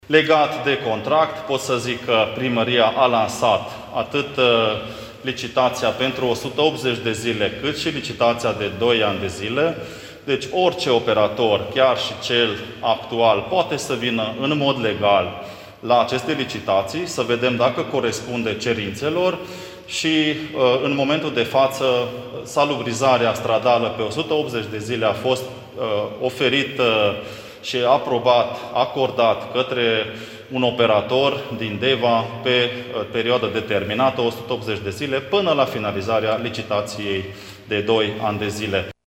Primarul mun. Tg.Mureș, Soos Zoltan a declarat presei că astăzi a fost anunțat operatorul de curățenie stradală că începând de mâine un alt operator va prelua sarcinile. Actualul operator asigură salubrizarea în oraș pe baza unui contract încheiat în luna iunie a anului trecut, în care nu este prevăzută nici curățenia de primăvară, nici colectarea selectivă a deșeurilor.